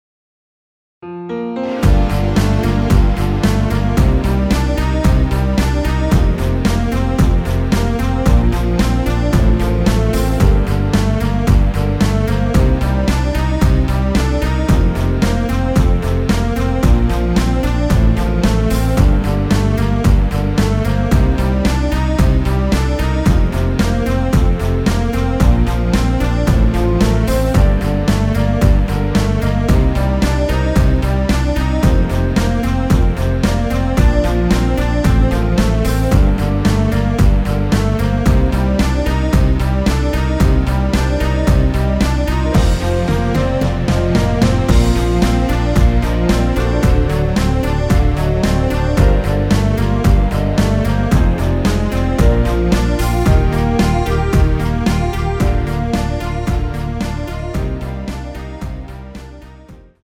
(-3)내린멜로디 포함된 MR 입니다.(미리듣기 참조)
Bb
앞부분30초, 뒷부분30초씩 편집해서 올려 드리고 있습니다.
중간에 음이 끈어지고 다시 나오는 이유는